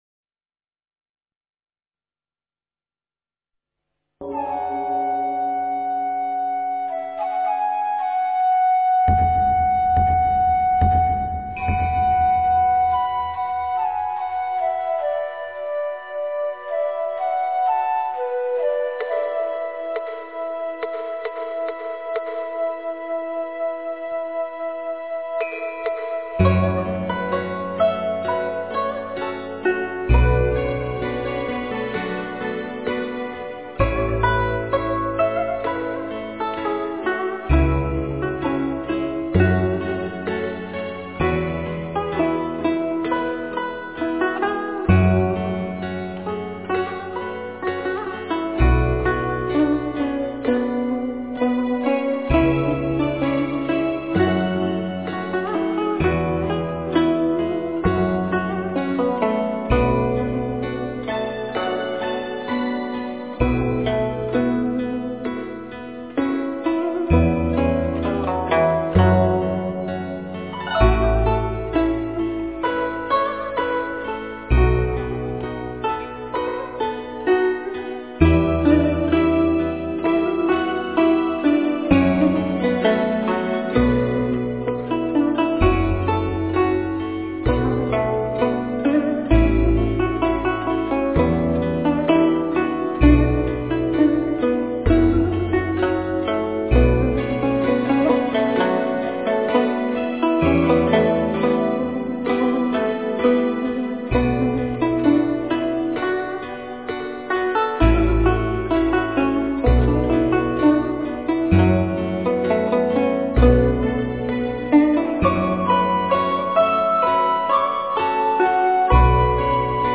诵经
佛音 诵经 佛教音乐 返回列表 上一篇： 大悲咒 下一篇： 大悲咒(童音版